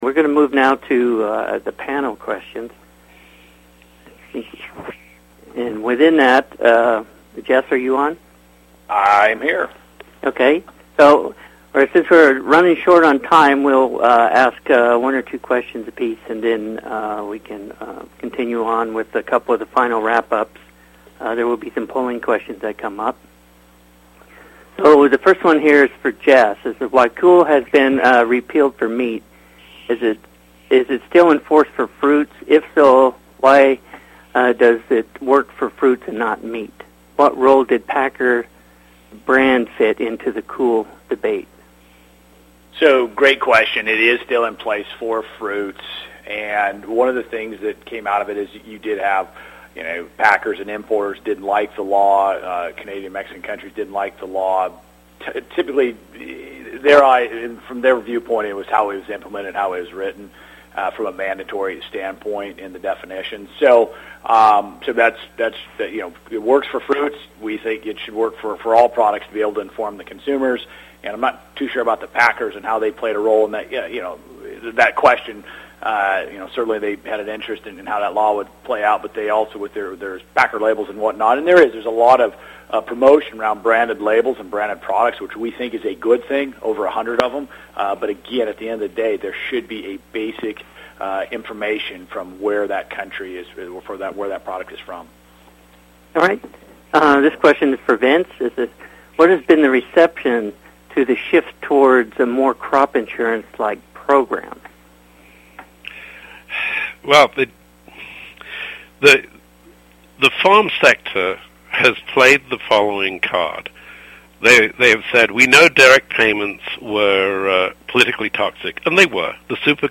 Sections for this webinar include: